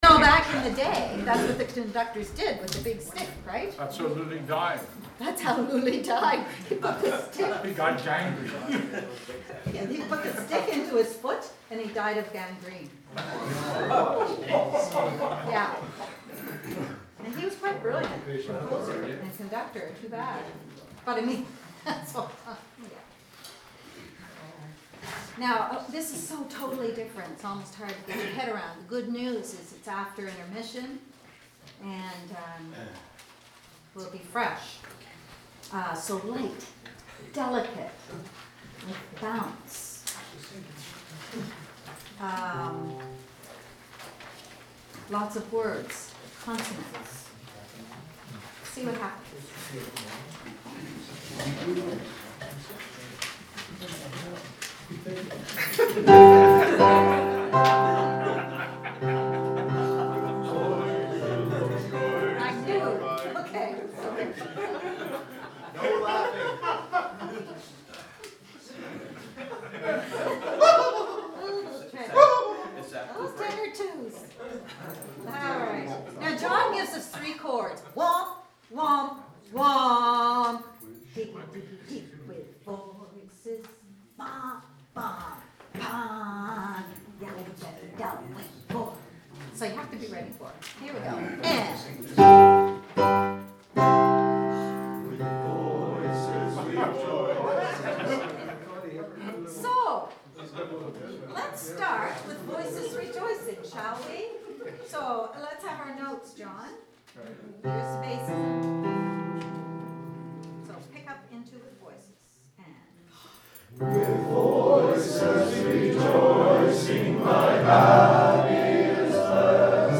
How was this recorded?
Rehearsal Files